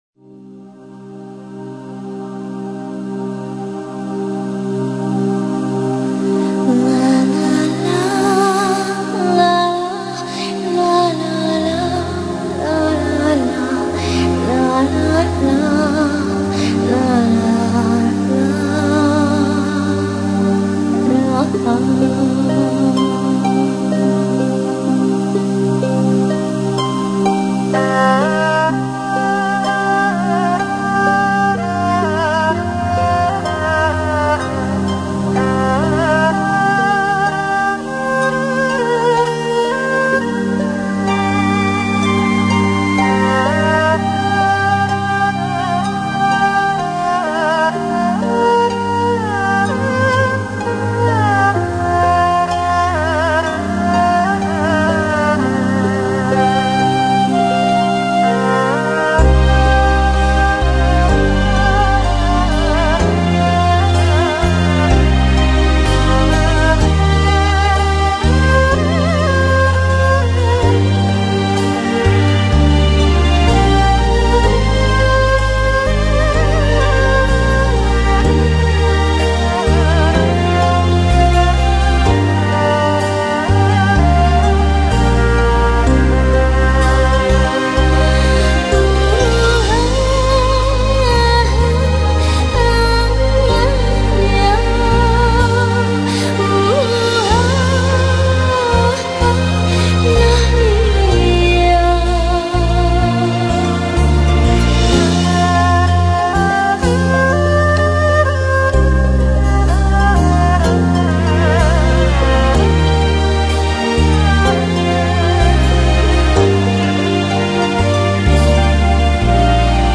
采用现代编曲手法，技巧性的运用水晶、二胡、古筝、排箫、洞萧、琵琶、扬琴、中音笙…等乐器
旋律清幽抒情